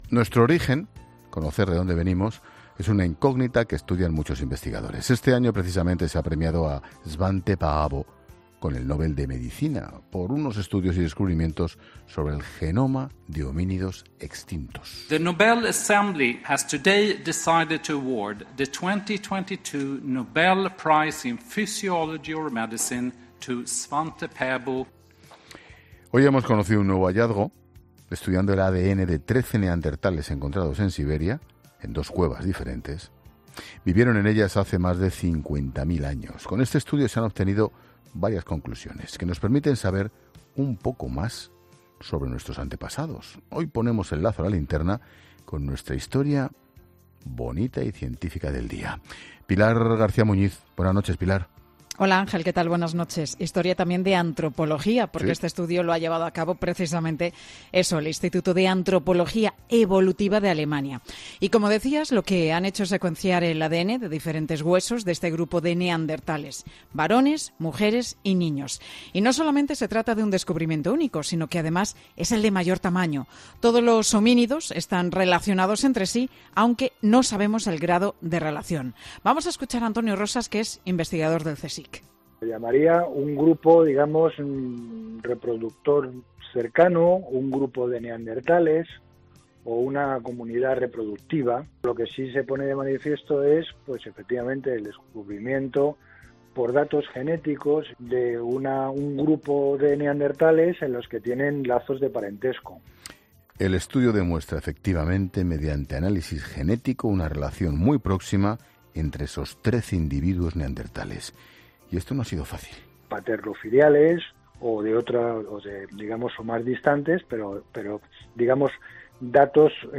investigador del CSIC explica un descubrimiento realizado por el Instituto de Antropología Evolutiva de Alemania